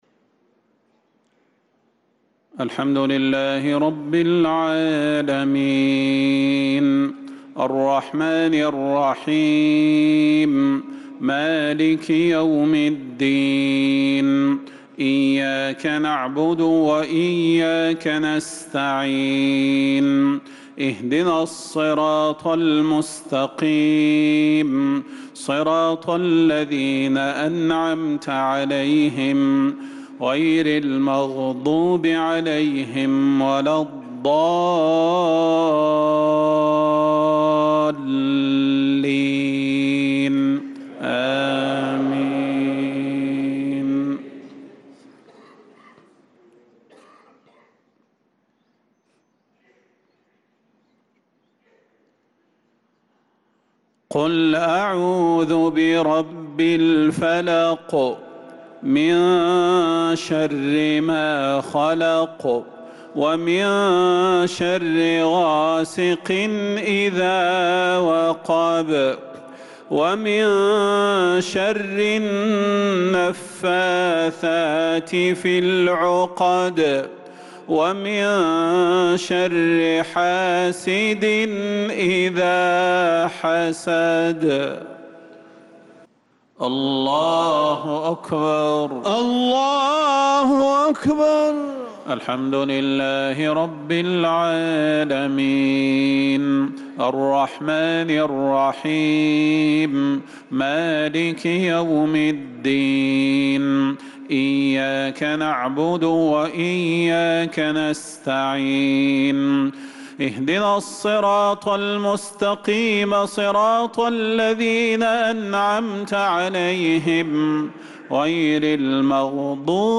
صلاة المغرب للقارئ صلاح البدير 13 شوال 1445 هـ
تِلَاوَات الْحَرَمَيْن .